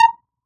edm-perc-18.wav